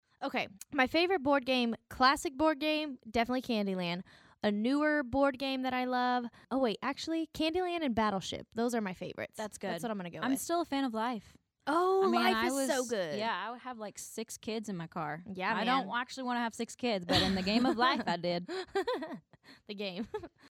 Audio / Maddie & Tae reveal their favorite classic board games.
Maddie & Tae (favorite board game) OC: (Maddie) …the game. [laughs] :20